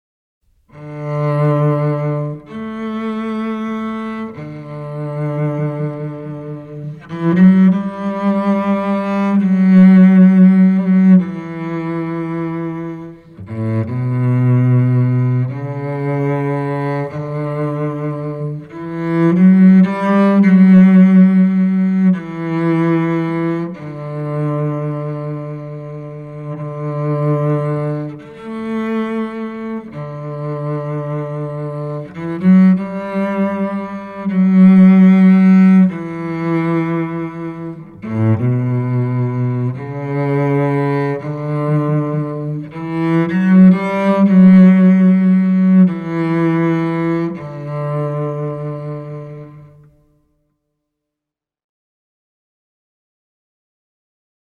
Solo cello Tracks recorded at FTM Studio in Denver, Colorado